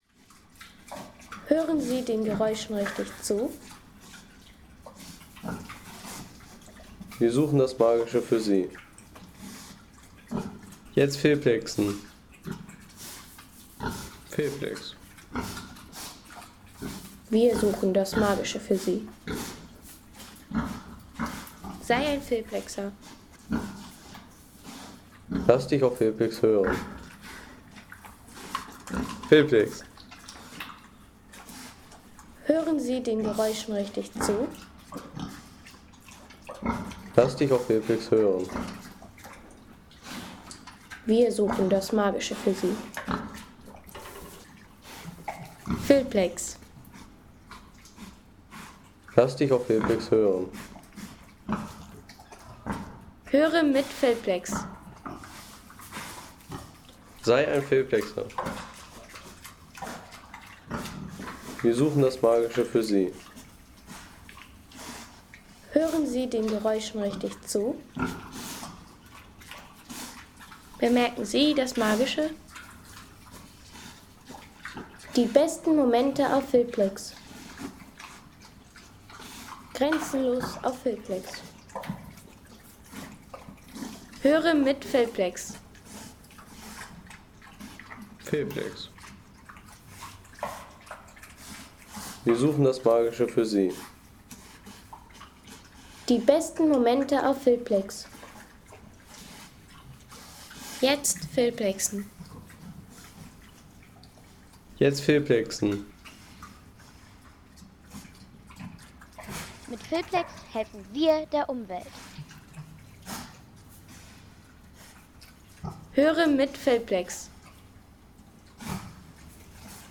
Schweinefütterung
Fütterung im Stall – Das Geräusch der hungrigen Schweine.